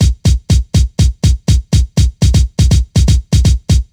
122 BPM Beat Loops Download